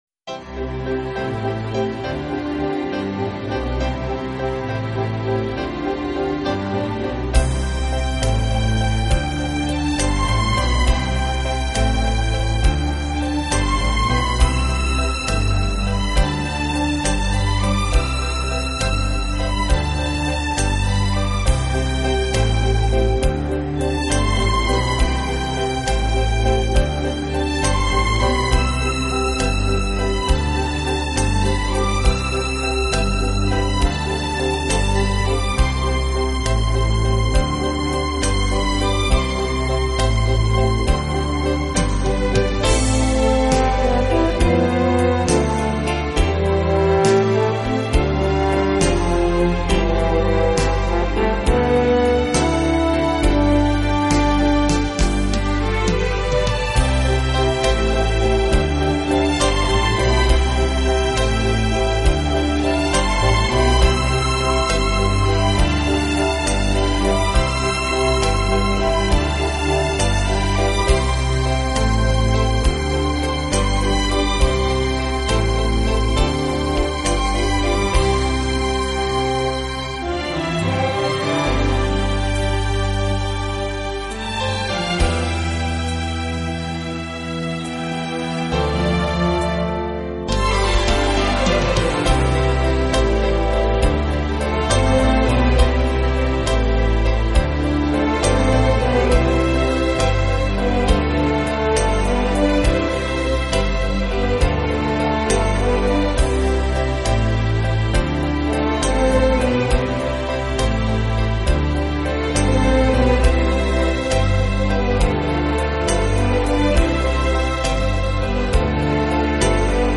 【轻音乐】
Genre: easy-listening